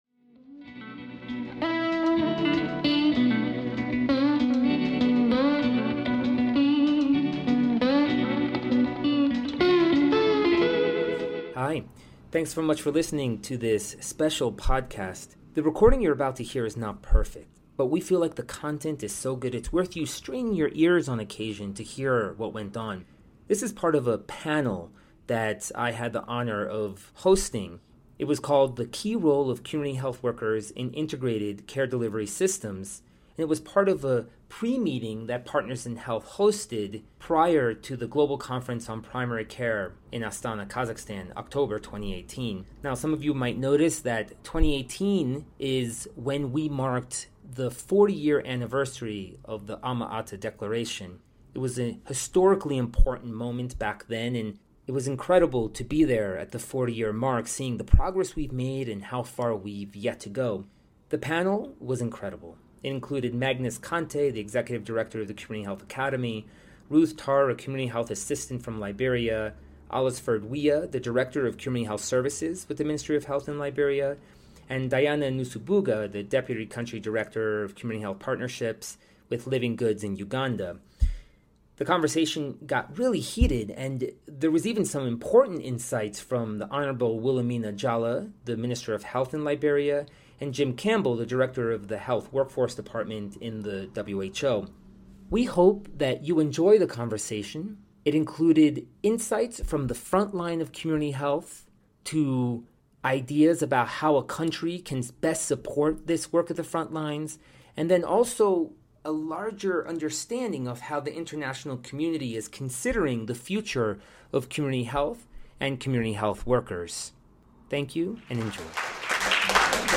Resource Type: Commentary, Discussion, Presentations